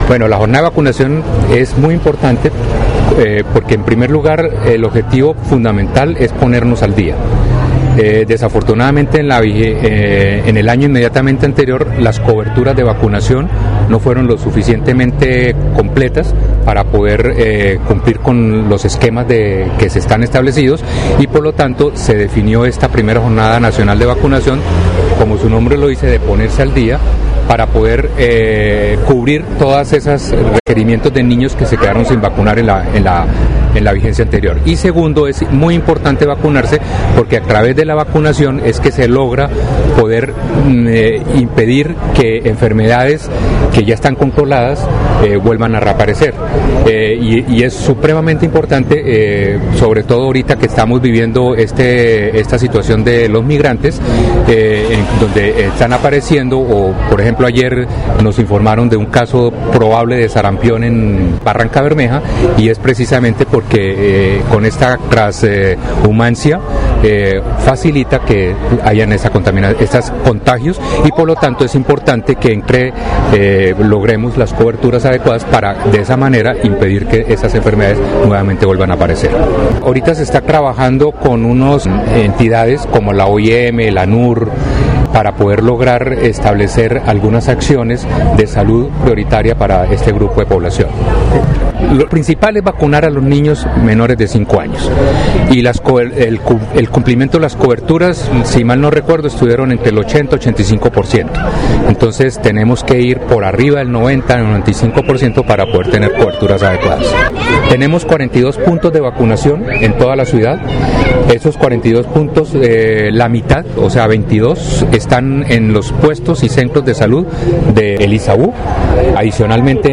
Nelson Ballesteros Vera, secretario de Salud y Ambiente de Bucaramanga
Nelson-Ballesteros-Vera-secretario-Salud-y-Ambiente-Bga-ok.mp3